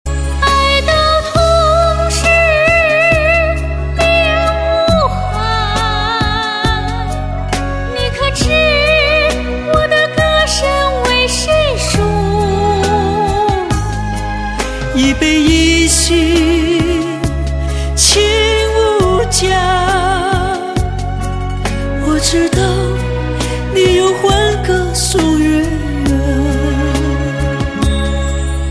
M4R铃声, MP3铃声, 华语歌曲 111 首发日期：2018-05-15 09:07 星期二